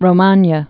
(rō-mänyə, -mänyä)